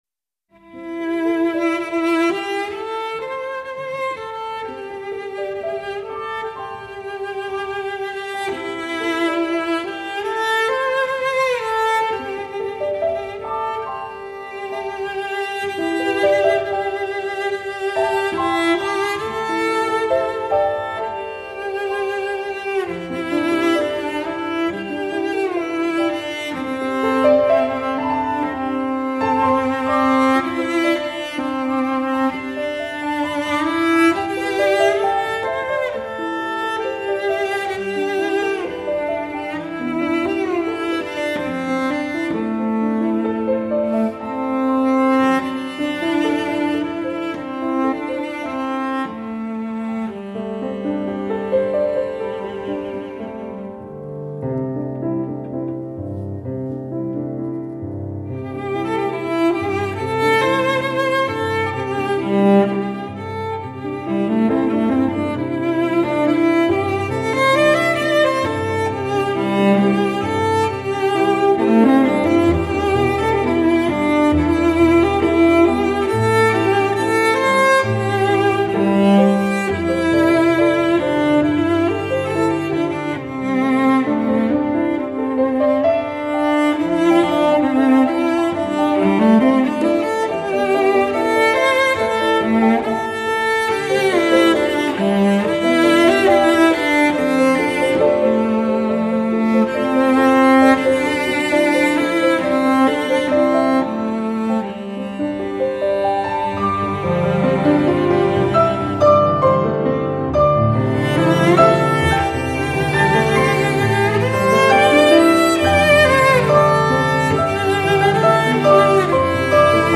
两缕琴声 几世怀想 交缠 沉吟 如影随形……
翩翩 藉由中西优美旋律缠绵动人